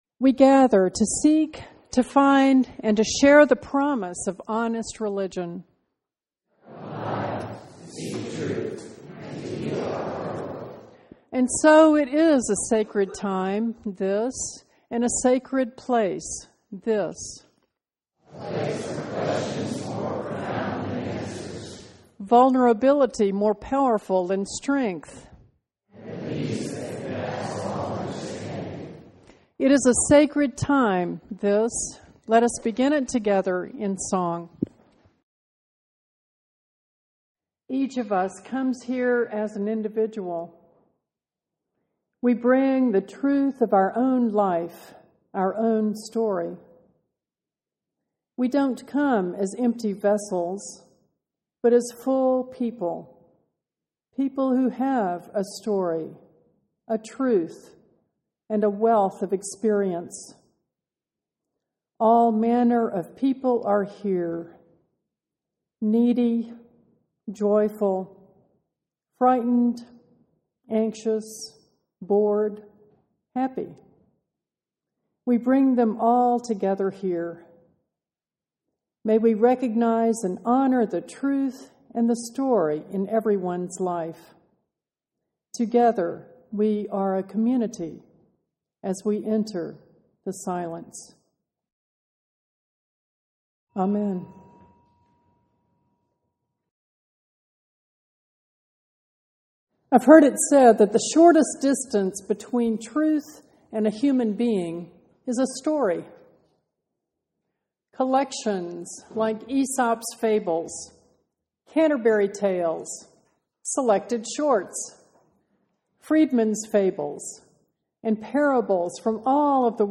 2009 Text of this sermon is not available but you can listen by clicking the play button.